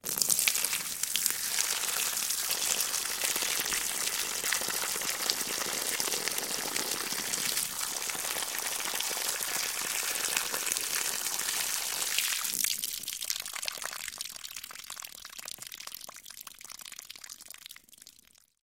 Звуки мочеиспускания
Звук струи мочи человека на землю или на пол